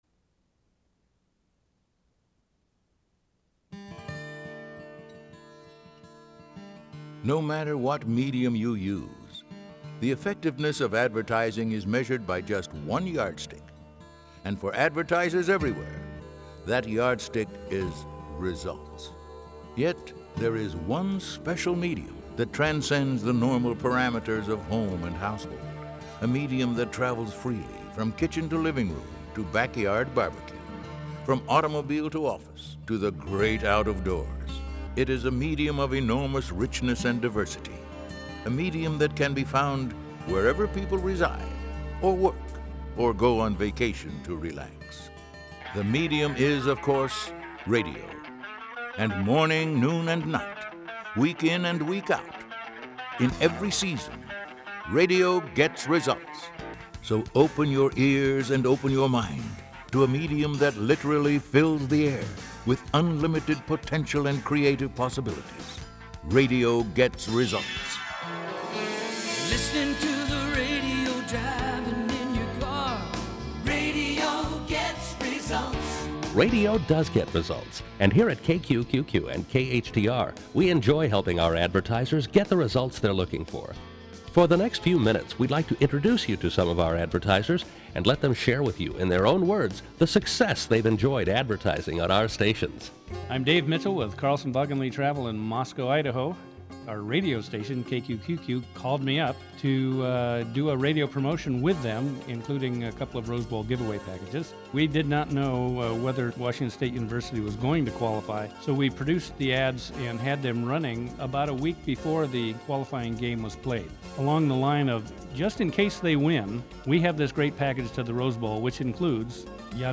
The fact that I retrieved this presentation from a cassette tape tells you something about how long ago I put it together.
Thinking about recent client success stories and how best to share them at RSC reminded me of this presentation I cobbled together (when we were still recording on 4-track tapes) to share some of my clients’ success stories with prospective advertisers.